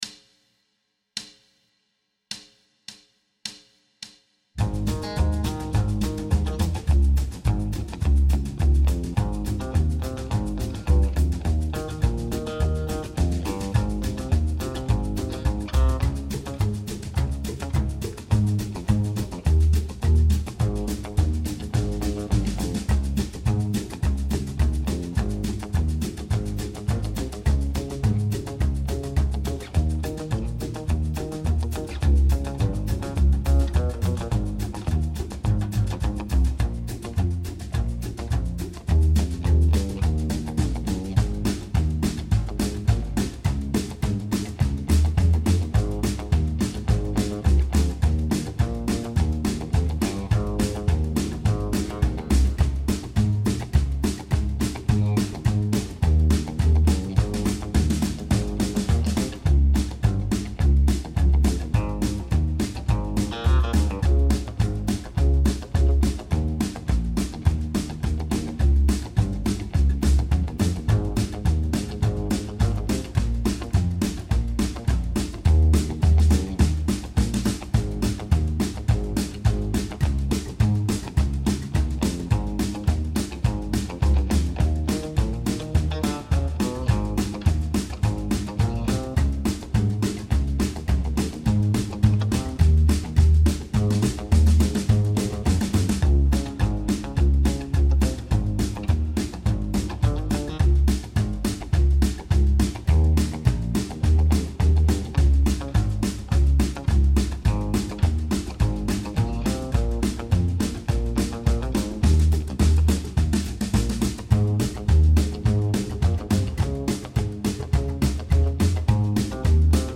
Rockabilly 8 Bar Blues
Rockabilly jam tracks. 8 bar blues, 50’s / 60’s style.
Tempo: 105BPM
Chord Progression: I-IV7-V7
Key of Ab – without guitar
rockabilly-8-bar-105-ab-no-guitar.mp3